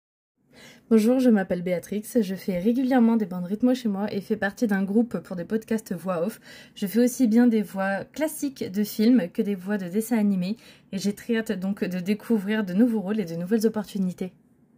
Voix off
Brève Présentation (voix normale)